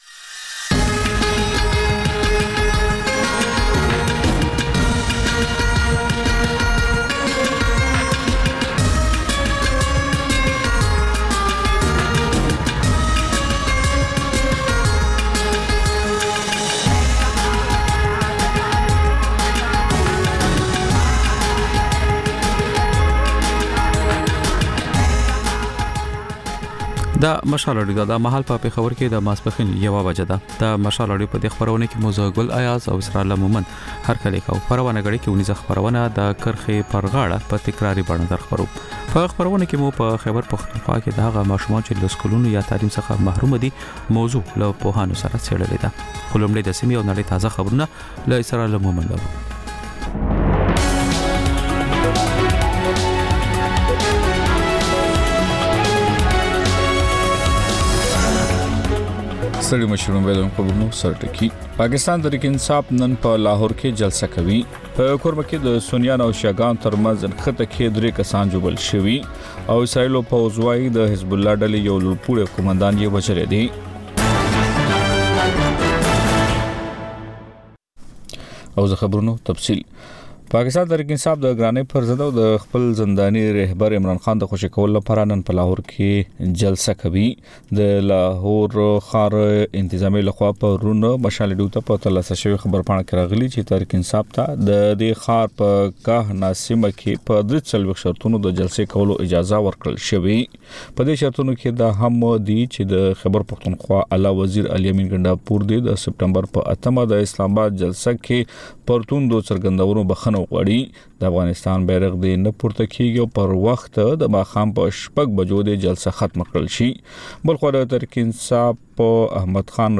د مشال راډیو لومړۍ ماسپښينۍ خپرونه. په دې خپرونه کې تر خبرونو وروسته بېلا بېل رپورټونه، شننې، مرکې خپرېږي. ورسره اوونیزه خپرونه/خپرونې هم خپرېږي.